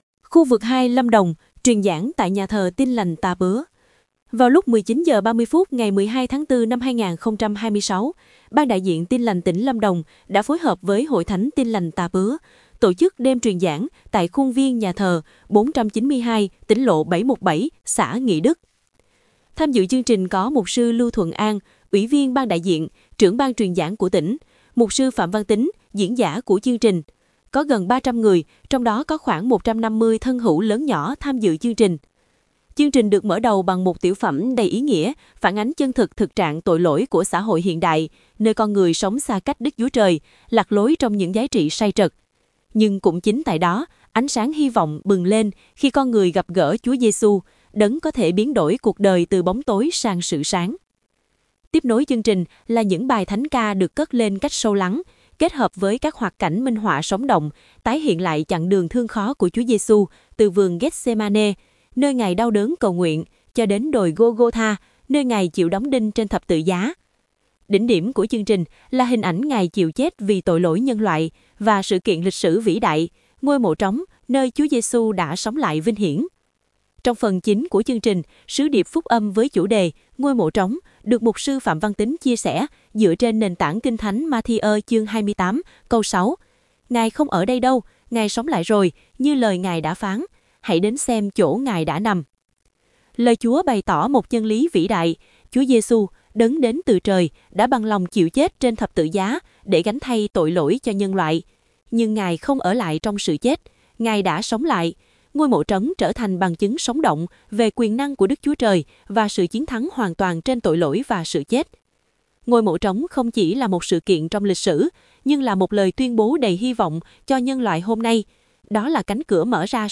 Khu vực 2 Lâm Đồng: Truyền giảng tại Nhà thờ Tin Lành Tà Pứa